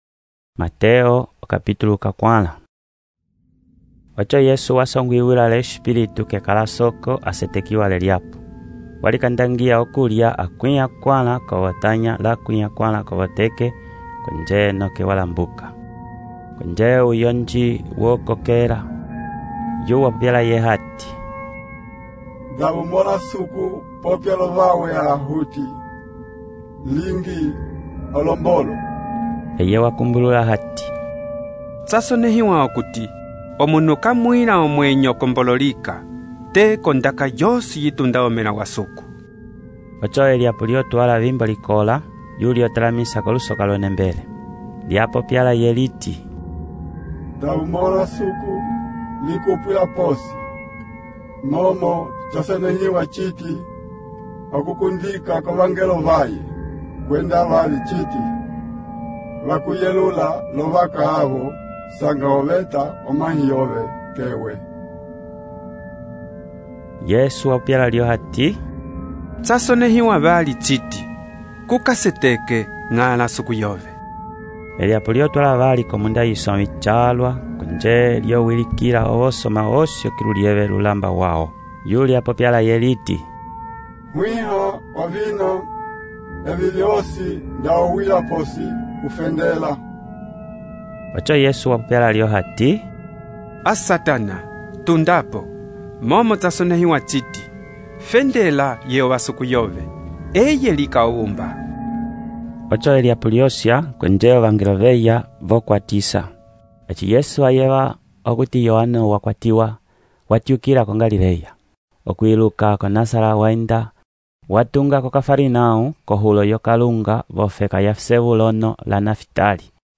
texto e narração , Mateus, capítulo 4